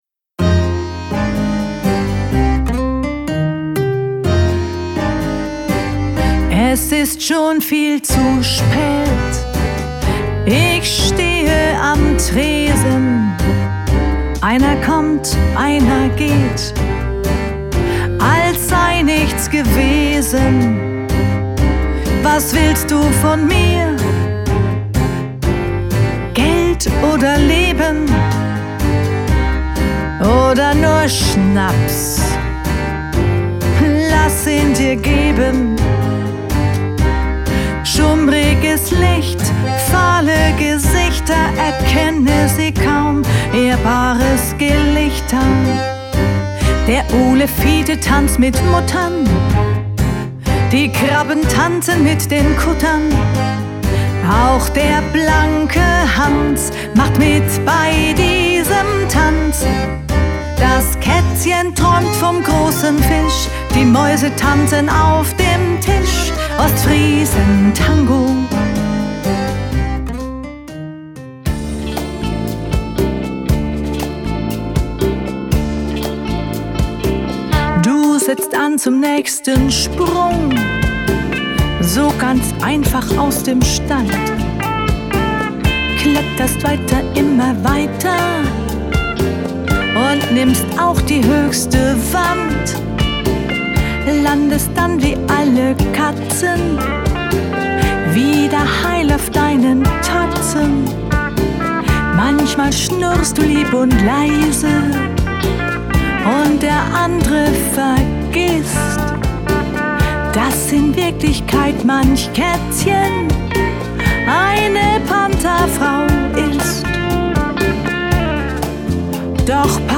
Krimi-Lieder
Themenwelt Kunst / Musik / Theater Musik Pop / Rock